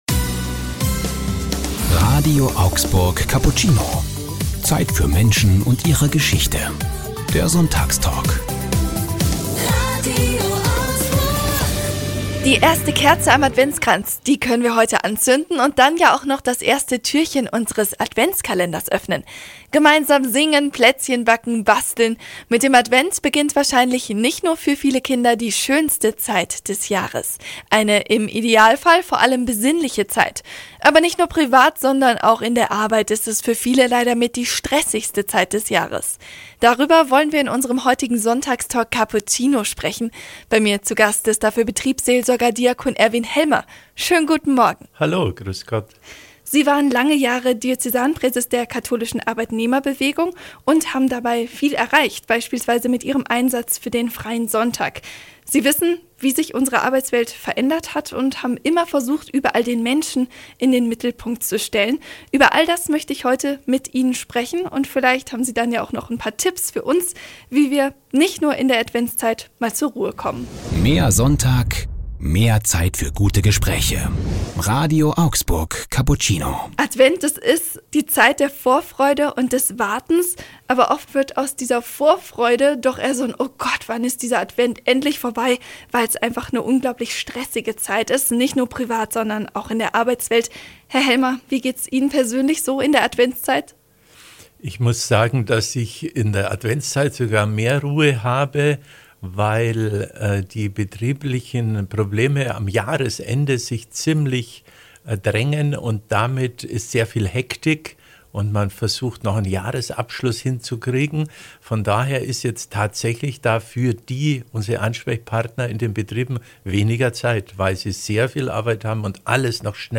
Sonntagstalk